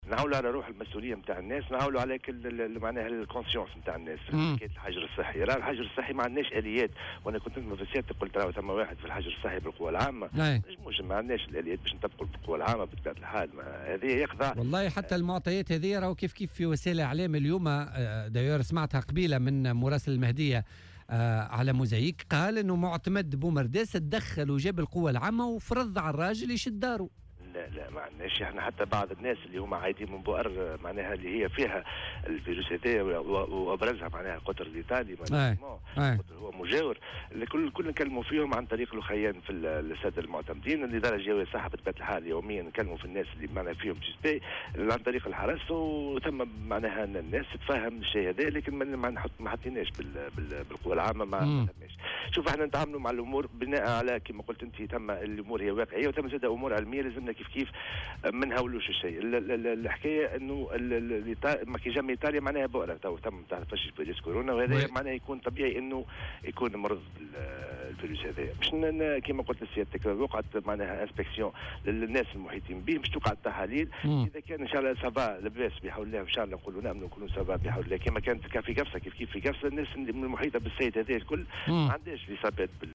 وقال في مداخلة له اليوم في برنامج "بوليتيكا" : " ليس لدينا آليات لتطبيق الحجر الصحي .. ونحن نعوّل على تفهّم المواطنين ووعيهم".